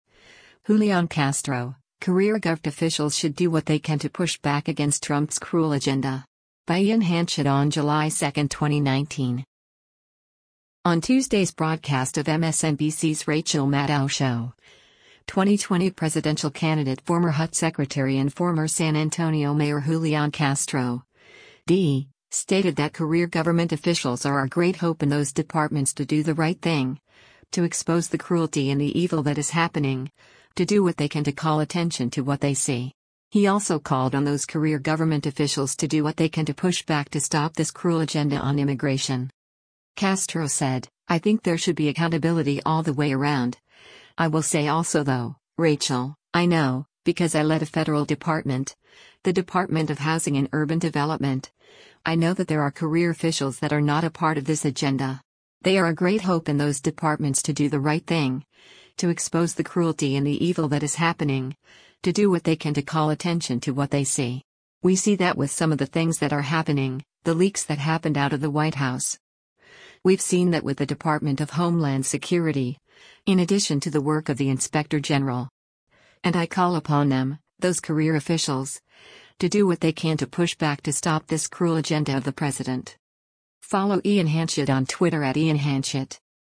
On Tuesday’s broadcast of MSNBC’s “Rachel Maddow Show,” 2020 presidential candidate former HUD Secretary and former San Antonio Mayor Julián Castro (D) stated that career government officials “are our great hope in those departments to do the right thing, to expose the cruelty and the evil that is happening, to do what they can to call attention to what they see.” He also called on those career government officials “to do what they can to push back to stop this cruel agenda” on immigration.